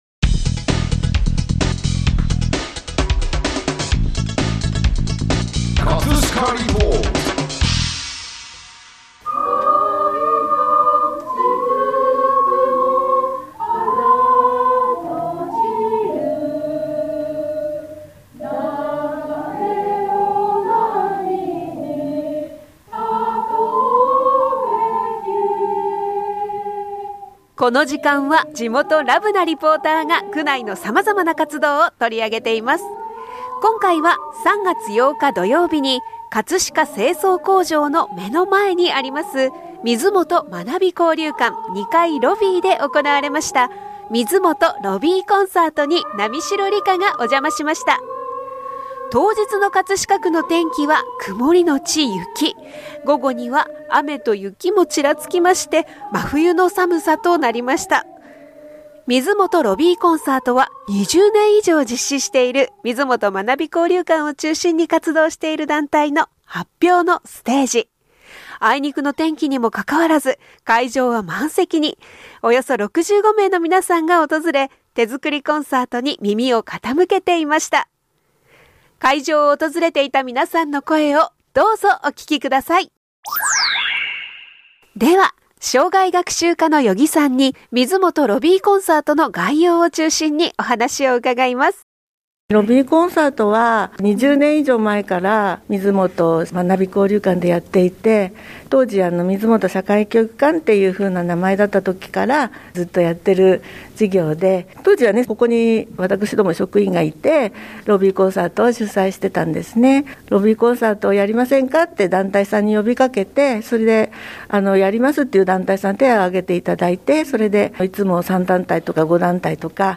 【葛飾リポート】 今回は3月8日㈯に葛飾清掃工場の目の前にあります水元学び交流館2階ロビーで行われました「水…
水元ロビーコンサートは20年以上実施している、水元学び交流館を中心に活動している団体の発表のステージ。 あいにくの天気にもかかわらず、会場はほぼ満席となり、およそ65人の皆さんが訪れ、手作りコンサートに耳を傾けていました。 会場を訪れていた皆さんの声をどうぞお聞きください！